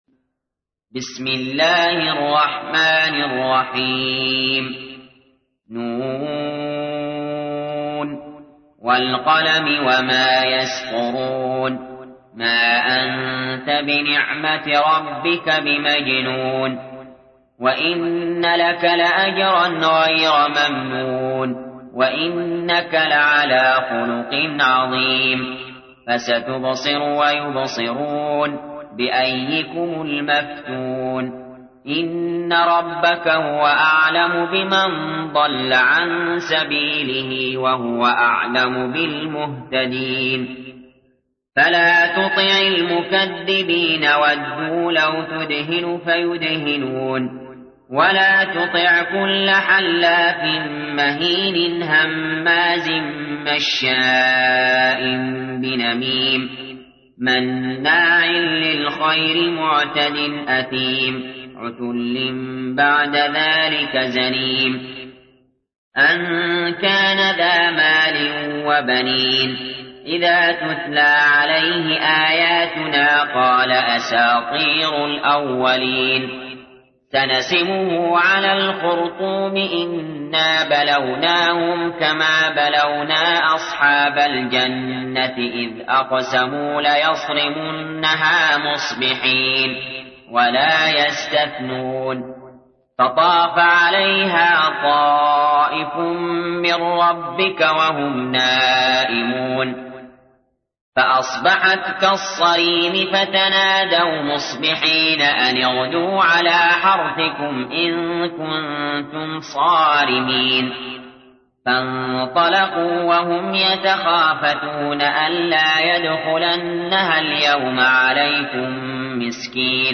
تحميل : 68. سورة القلم / القارئ علي جابر / القرآن الكريم / موقع يا حسين